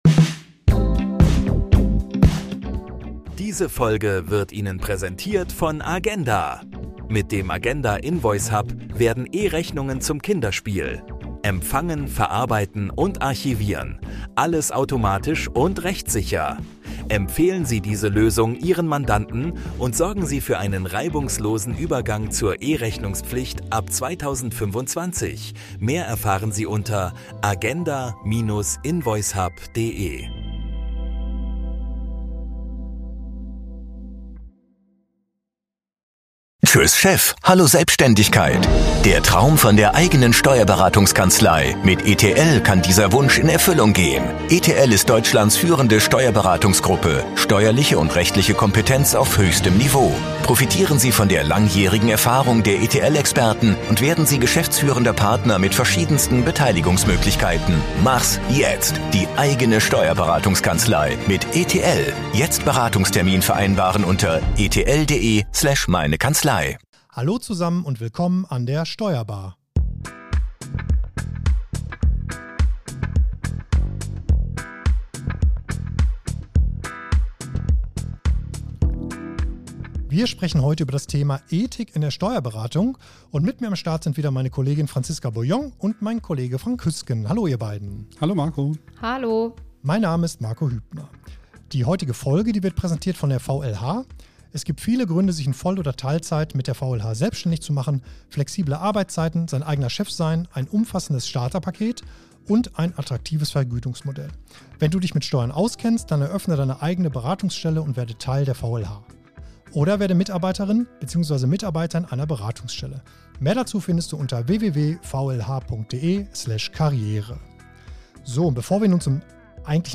Experteninterviews rund um die Themen Wirtschaft, Steuern und Recht.